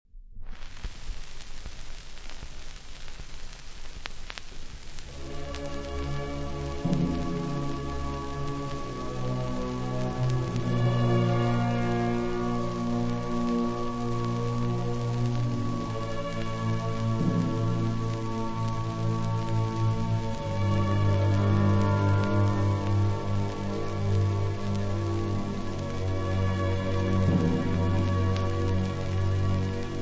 • arie